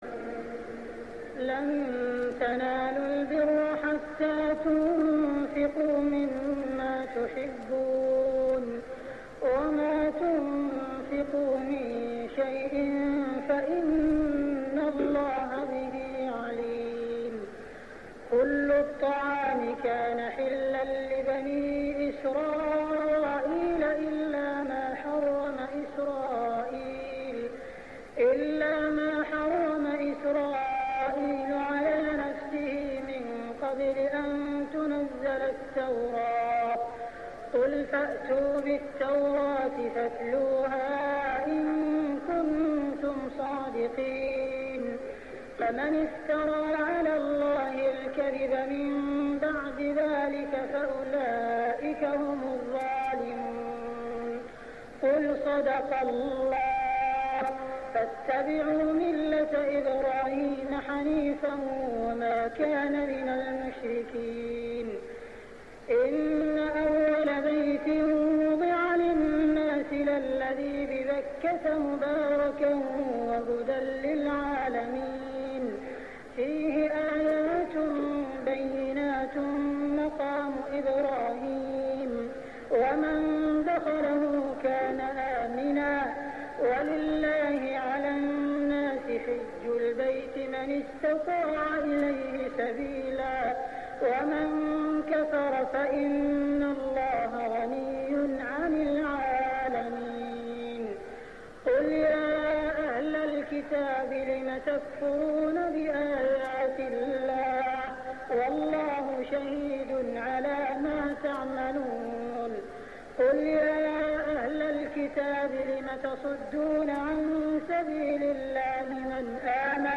صلاة التراويح ليلة 5-9-1407هـ سورة آل عمران 92-163 | Tarawih Prayer Surah Al Imran > تراويح الحرم المكي عام 1407 🕋 > التراويح - تلاوات الحرمين